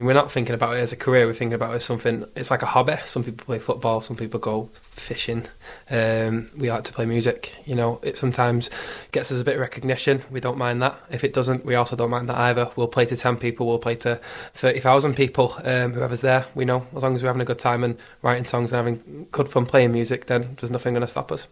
Project Aura Interview